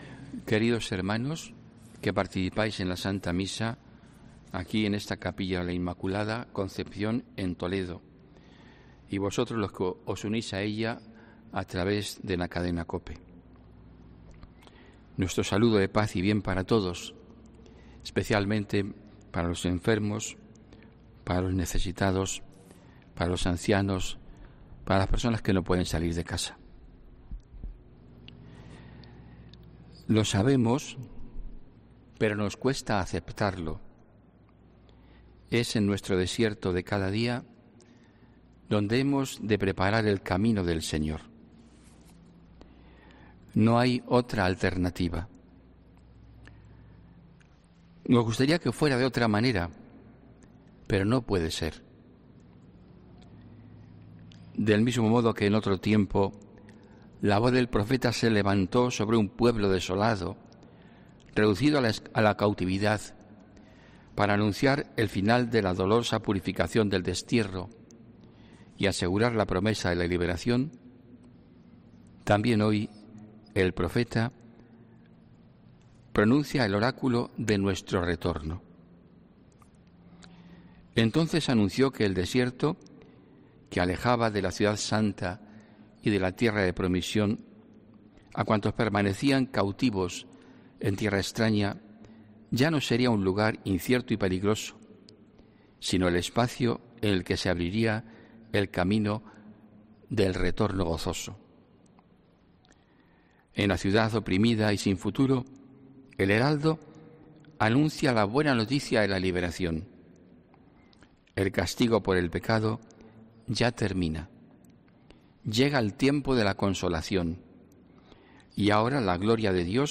HOMILÍA 6 DICIEMBRE 2020